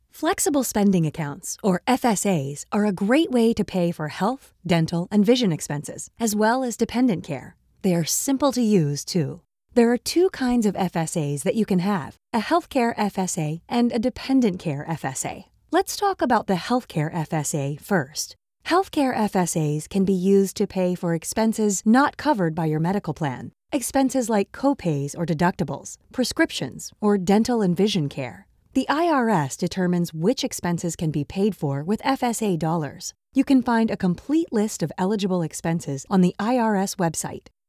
Seasoned voiceover actor with a broad range of skills
Healthcare Demo